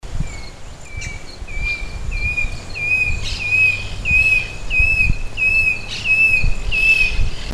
Nothoprocta cinerascens
Nome em Inglês: Brushland Tinamou
Localidade ou área protegida: Reserva privada Don Felix y Sacha Juan
Condição: Selvagem
Certeza: Observado, Gravado Vocal
InambuMontaraz.mp3